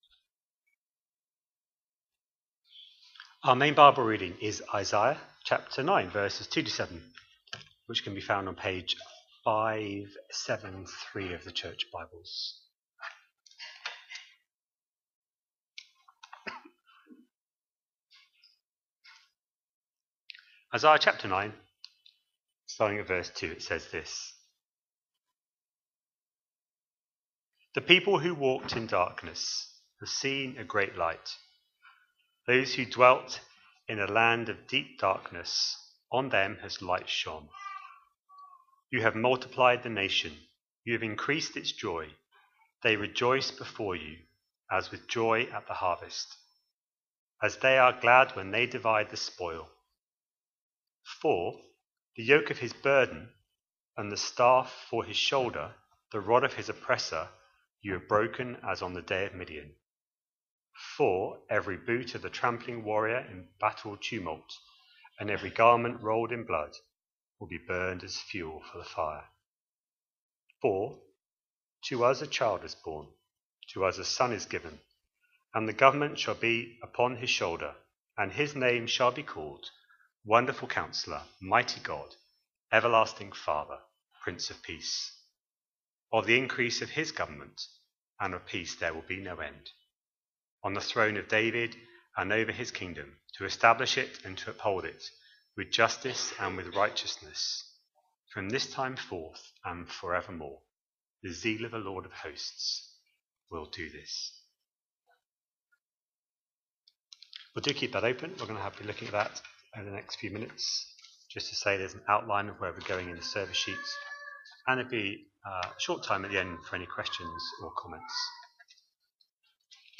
A sermon preached on 14th December, 2025, as part of our Christmas 2025 series.